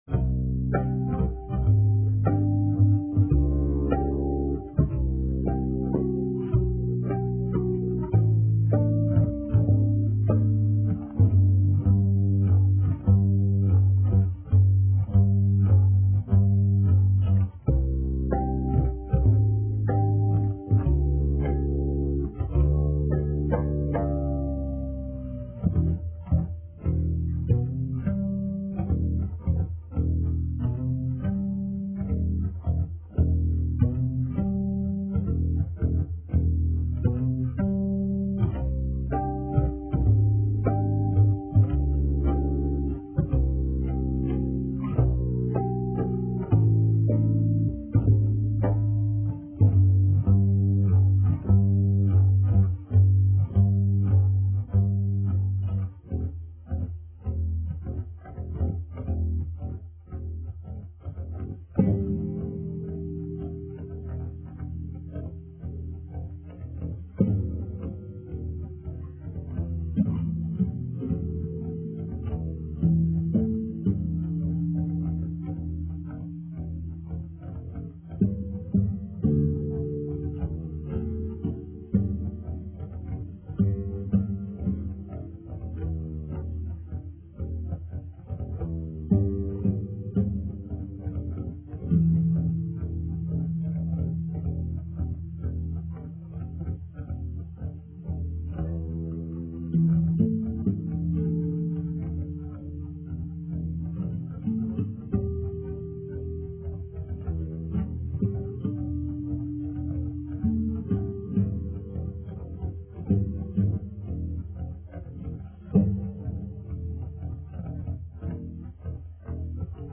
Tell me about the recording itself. Recorded at Personal Studio, between March-July 2001.